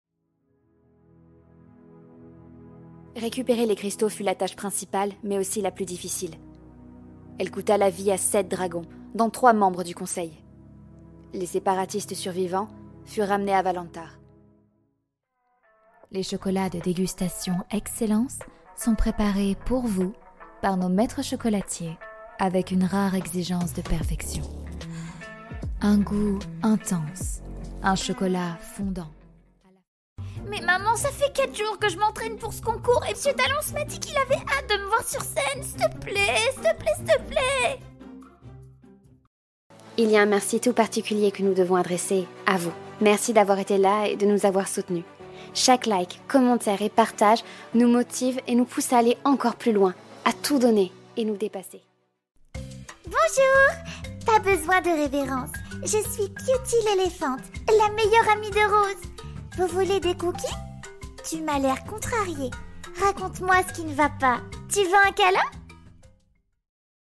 Démo voix-off
3 - 30 ans - Mezzo-soprano